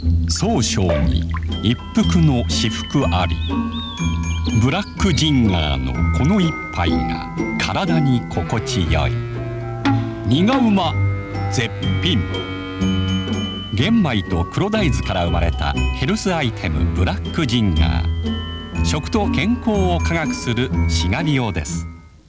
■ボイスサンプル
CM
低音から高音/トーンの使い分け
癒し系